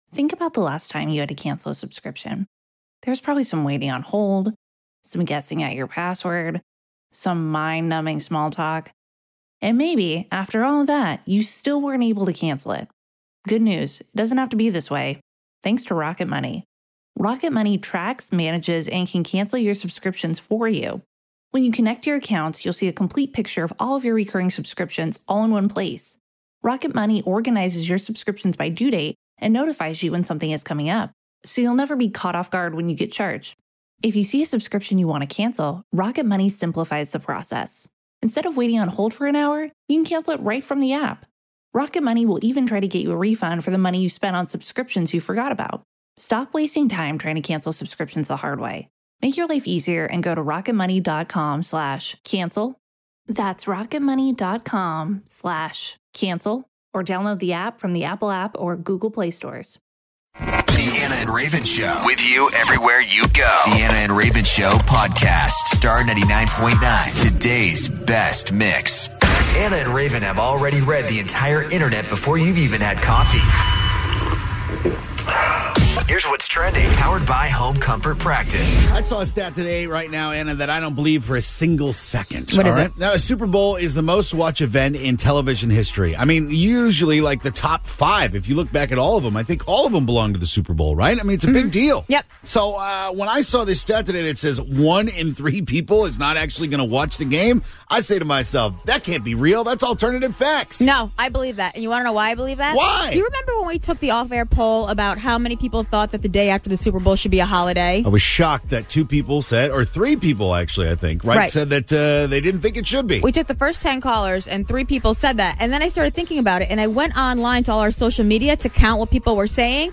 A clip of America the beautiful played by this barnyard animal.